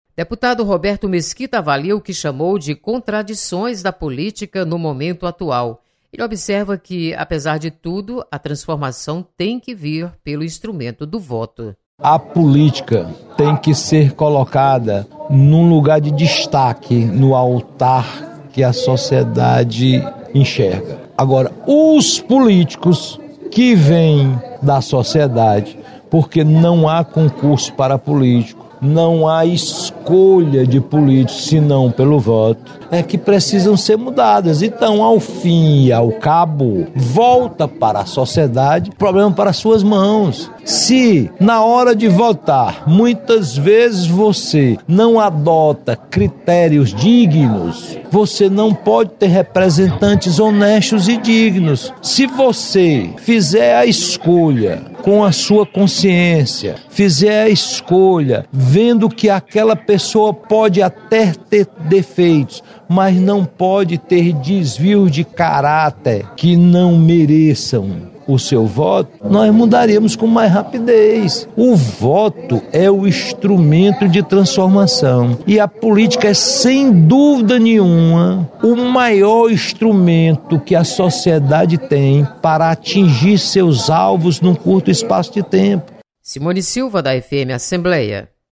Deputado Roberto Mesquita critica atual momento político vivido no país. Repórter